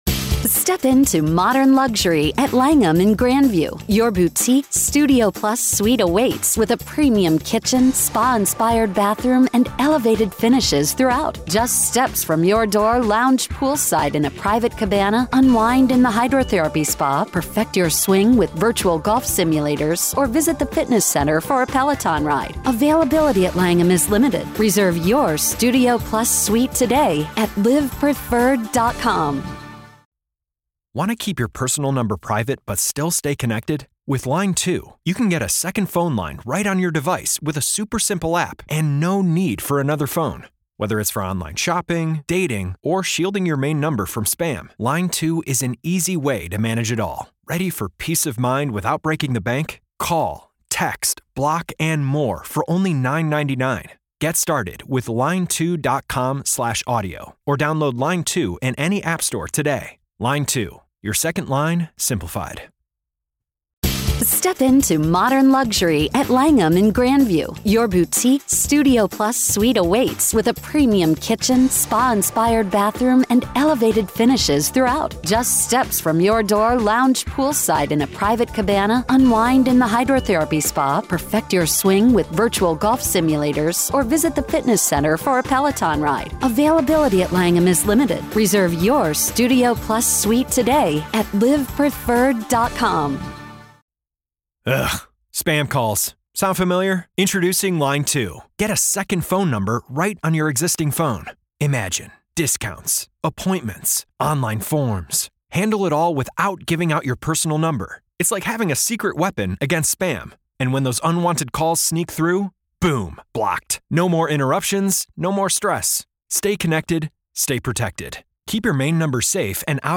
The revelations from the conversation not only touch on a specific law enforcement figure's alleged omissions and misconduct but also raise broader concerns about the existence of organized and covert "evil" in society.